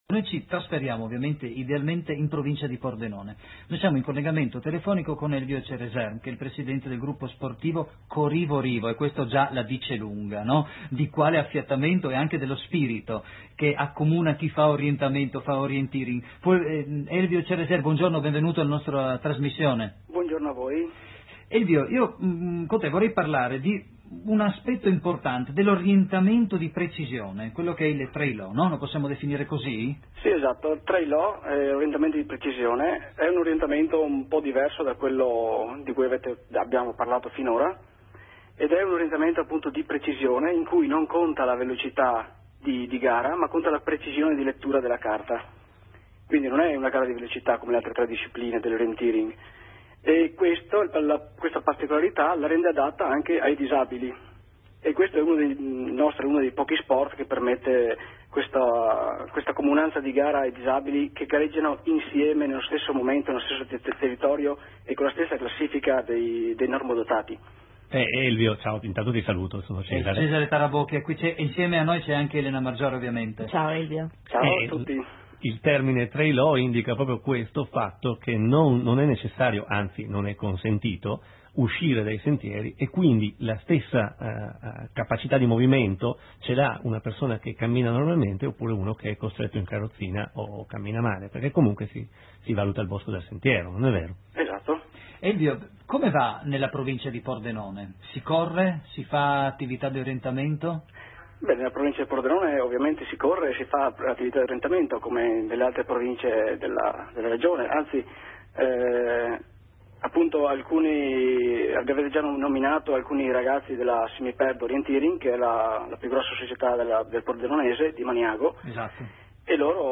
Intervista radiofonica